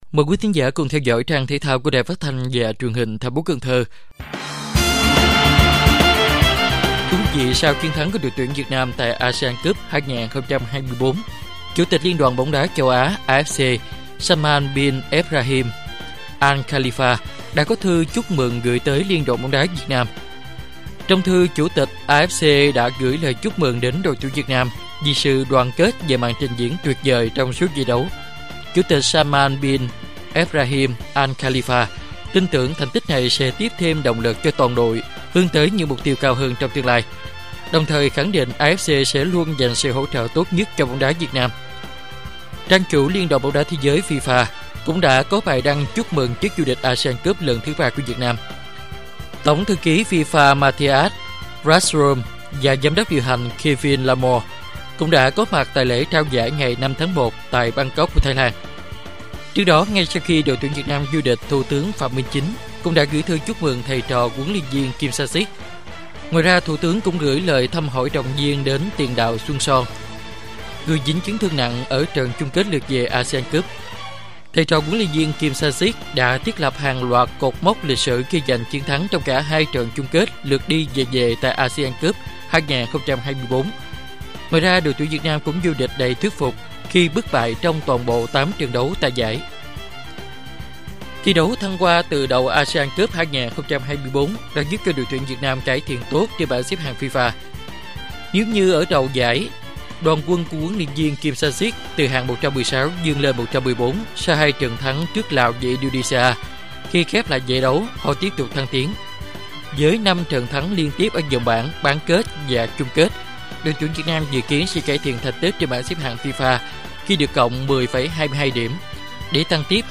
Bản tin thể thao 8/1/2025